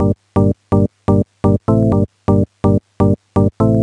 cch_synth_dxorg_125_Am.wav